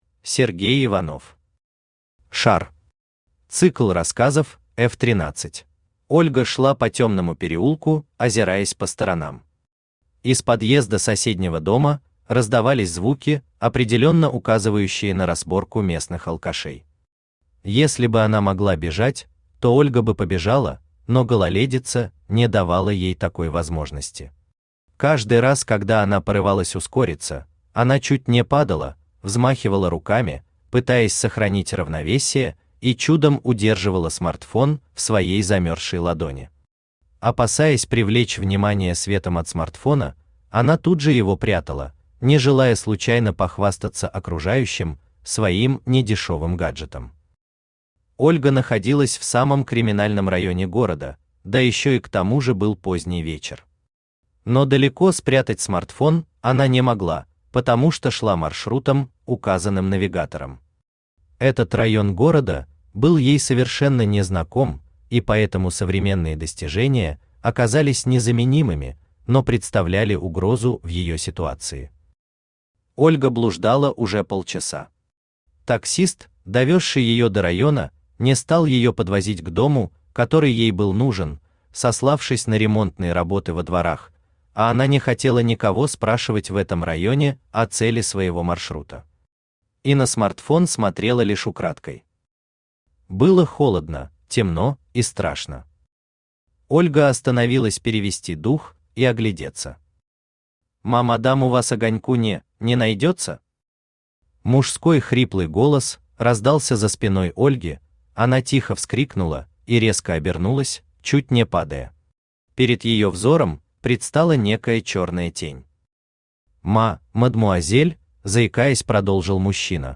Цикл рассказов F13 Автор Сергей Иванов Читает аудиокнигу Авточтец ЛитРес.